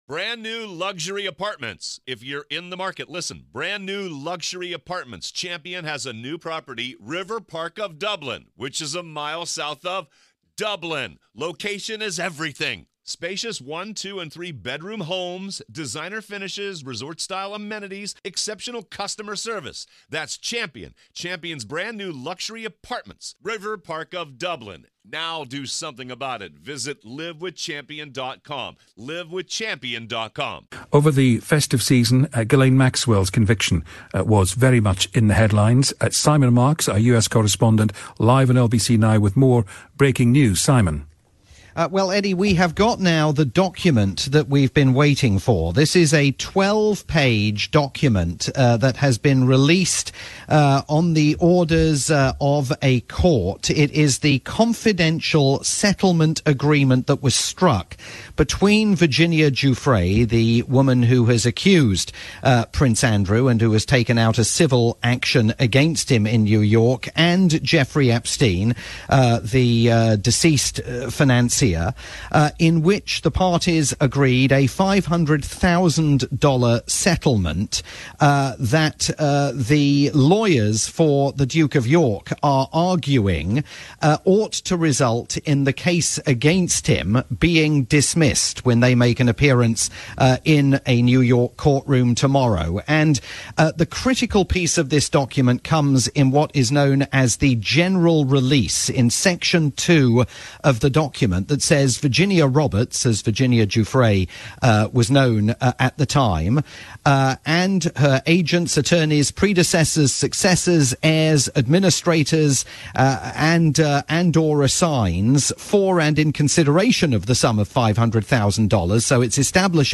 live breaking news report